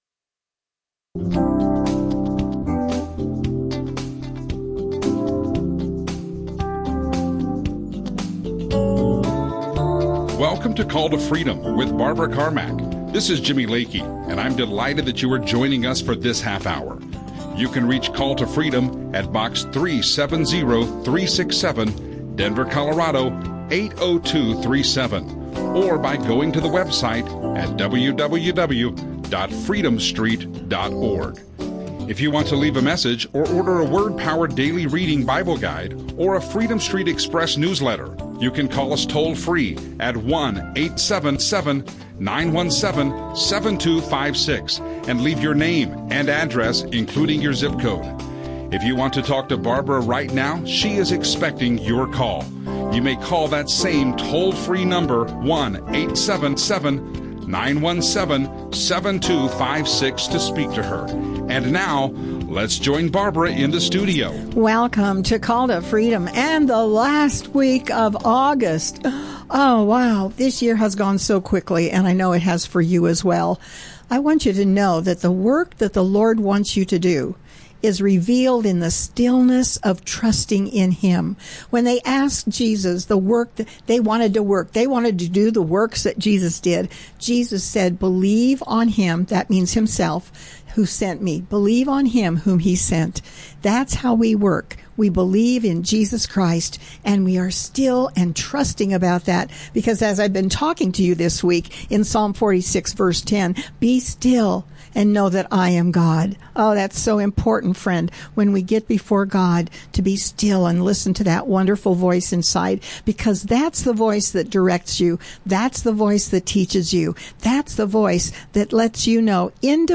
Christian talk show